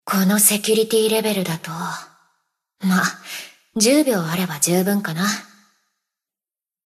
贡献 ） 分类:蔚蓝档案语音 协议:Copyright 您不可以覆盖此文件。
BA_V_Chihiro_Cafe_Monolog_5.ogg